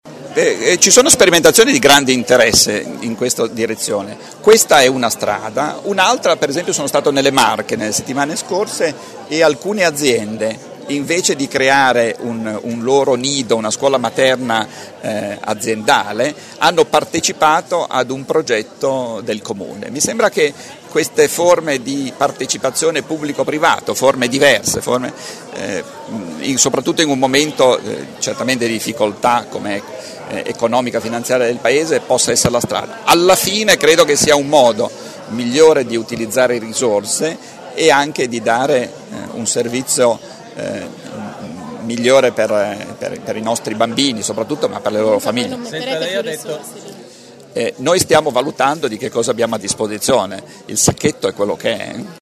A Bologna per un convegno in Regione su formazione professionale e ricerca industriale il Ministro ha portato anche buone notizie.